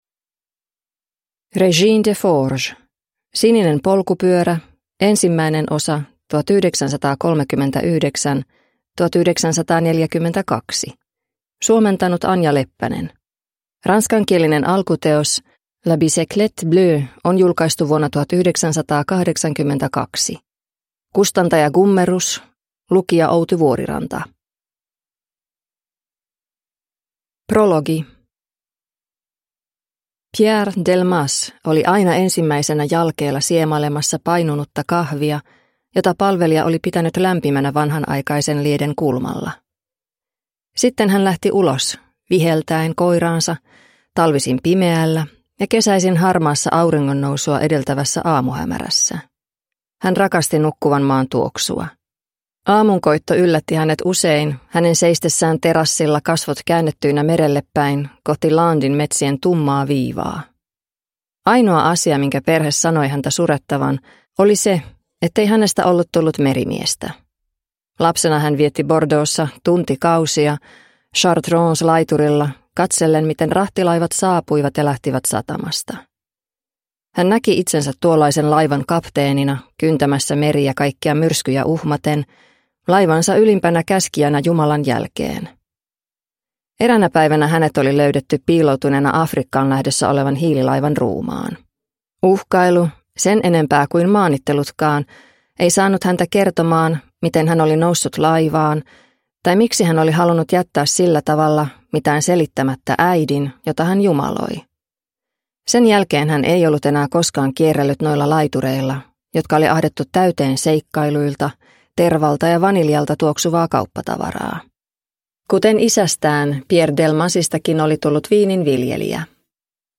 Sininen polkupyörä – Ljudbok – Laddas ner
Romanttisen jännityskirjallisuuden klassikko vihdoinkin äänikirjana!